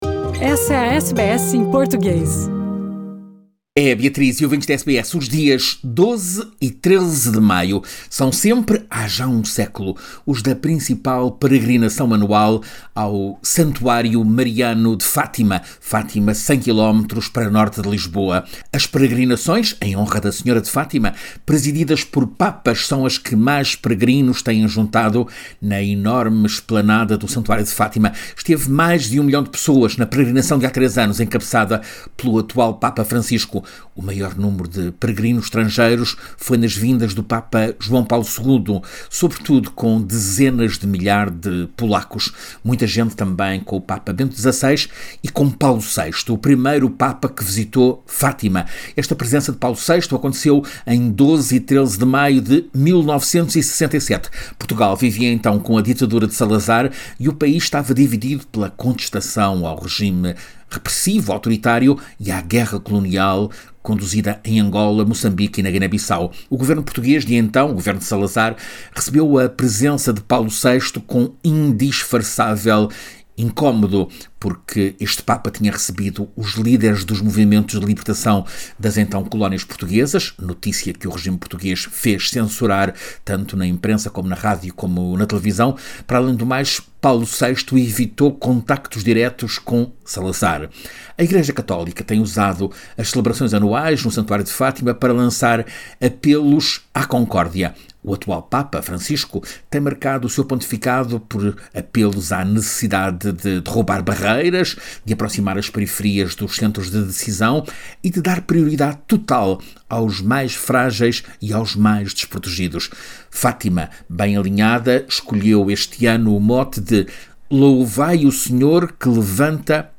SBS em Português